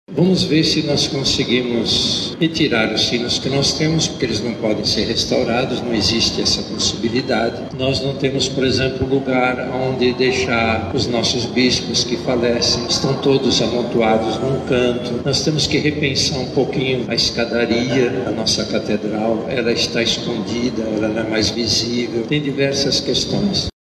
Durante a cerimônia de entrega da obra, o Cardeal da Amazônia e Arcebispo Metropolitano de Manaus, Dom Leonardo Steiner, disse que assim como a Igreja de São Sebastião, a Catedral de Manaus também possui outras áreas que necessitam de restauração.